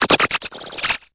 Cri de Sonistrelle dans Pokémon Épée et Bouclier.